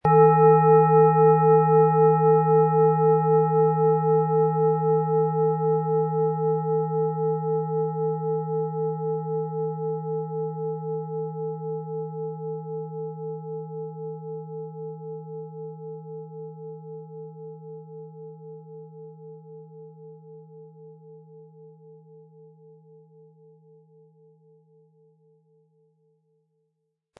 Es ist eine von Hand geschmiedete Klangschale, die in alter Tradition in Asien von Hand gefertigt wurde.
• Tiefster Ton: Eros
Im Sound-Player - Jetzt reinhören können Sie den Original-Ton genau dieser Schale anhören.
Mit Klöppel, den Sie umsonst erhalten, er lässt die Planeten-Klangschale Biorhythmus Seele voll und harmonisch erklingen.
PlanetentöneBiorythmus Seele & Eros & Uranus (Höchster Ton)
MaterialBronze